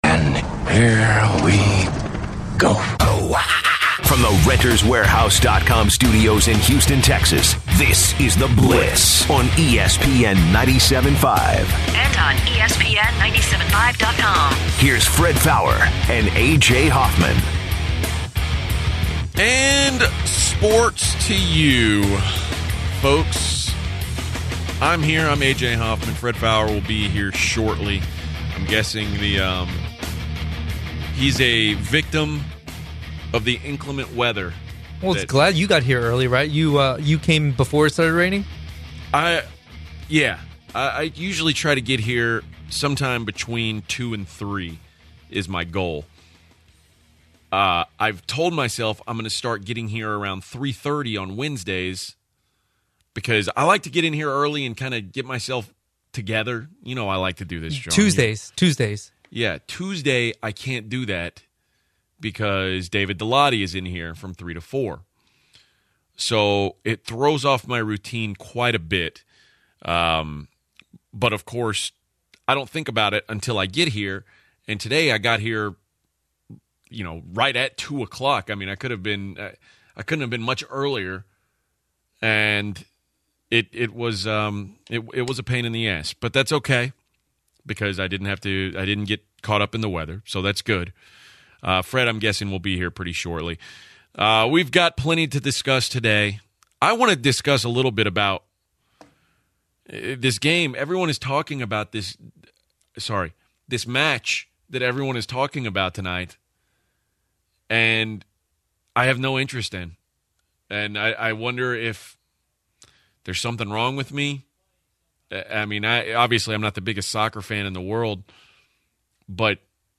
They also discuss the U.S Soccer national team’s upcoming match Vs. Argentina. The Blitzer’s call the show to talk Messi, Soccer and much more.